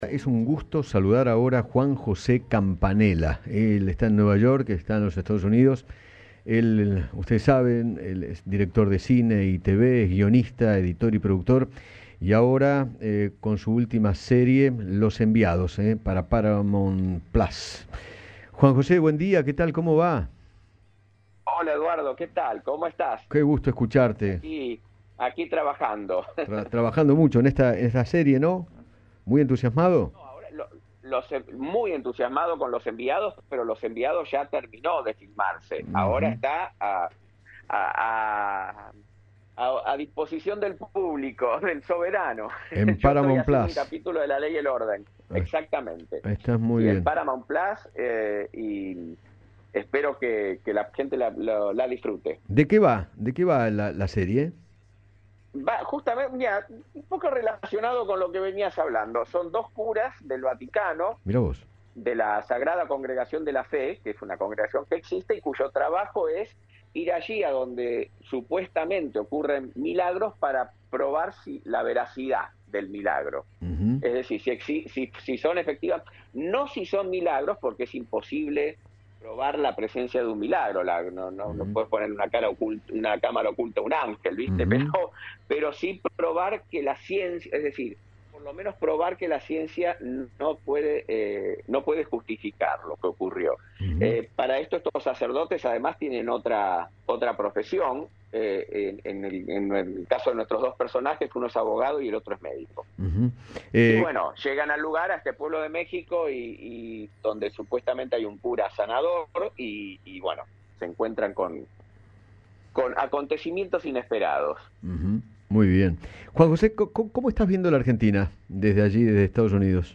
Juan José Campanella, director de cine y guionista, dialogó con Eduardo Feinmann sobre el presente del país y advirtió que “hay que ser cautos manejando las expectativas”.